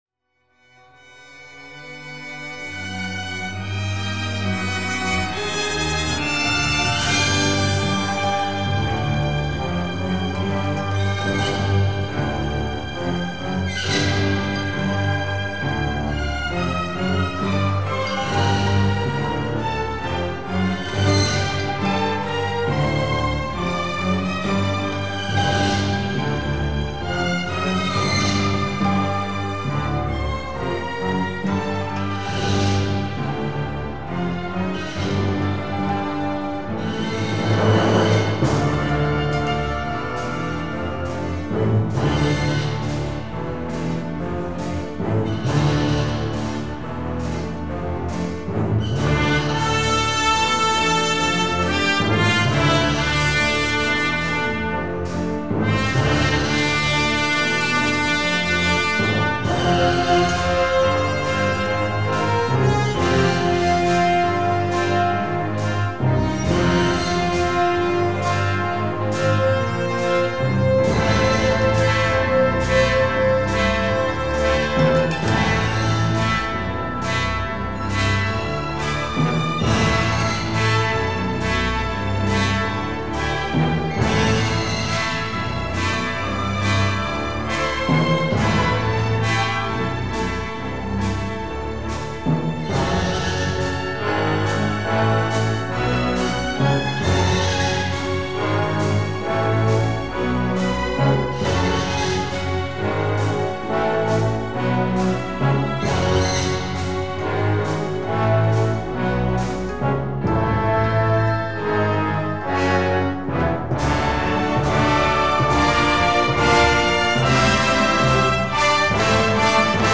Musica mediterranea di chiaro timbro 'rozsiano'
Di buona qualità.